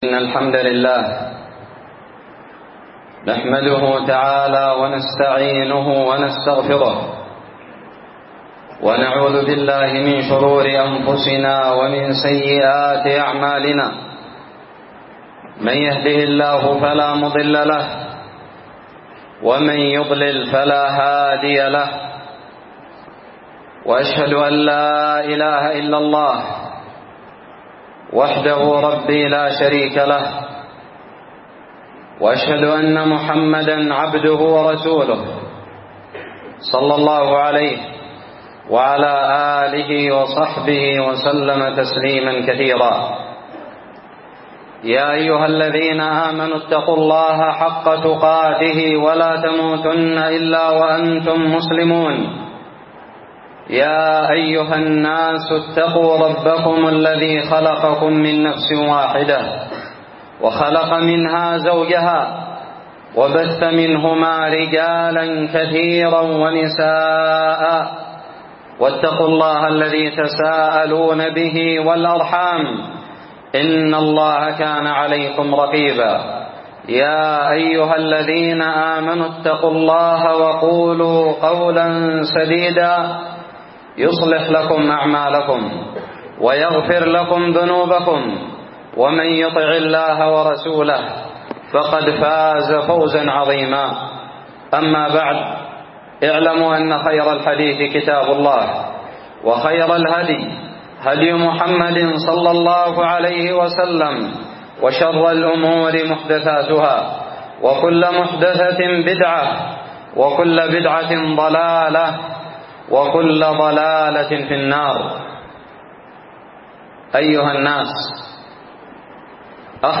خطب الجمعة
ألقيت بدار الحديث السلفية للعلوم الشرعية بالضالع في 20 شوال 1438هــ